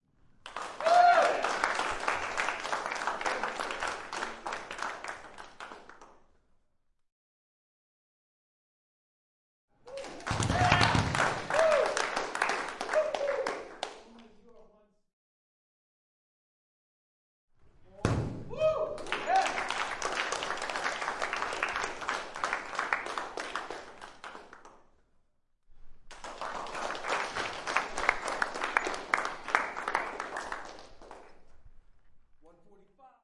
高中 " 掌声欢呼声中，高中男生摔跤馆的回声各种
描述：掌声欢呼int中高中男生摔跤健身房呼应各种各样
Tag: 欢呼 健身房 INT 掌声 媒体 学校